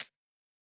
UHH_ElectroHatB_Hit-24.wav